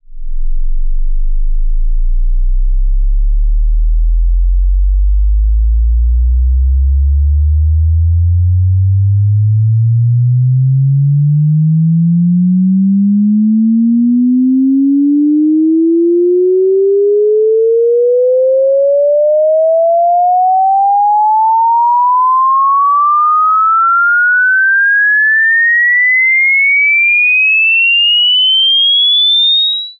test_sweep.wav